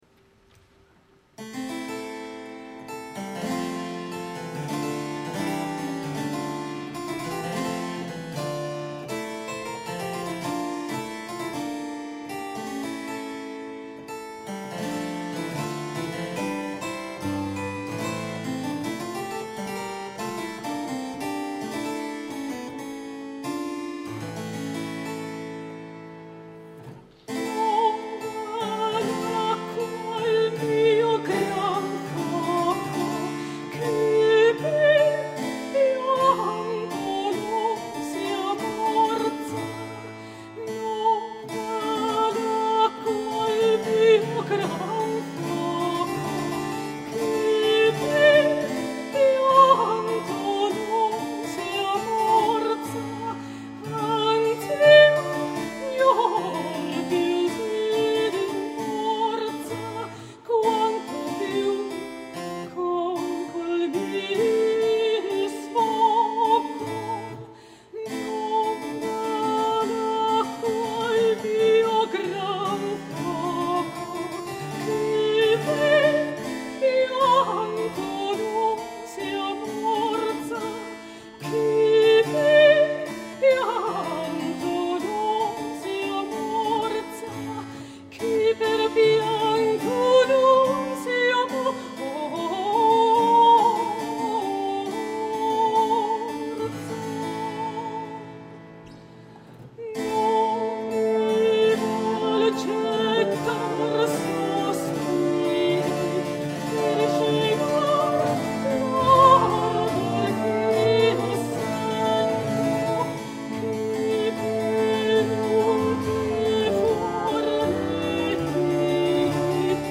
Templo de la Valenciana - Guanajato-Mexico - Festival Internacional Cervantino, 4 ottobre 2007
CONSORT VENETO:
soprano
flauto
Dulciana
cembalo
Registrazione a Cura di Radio Educàtion Mexico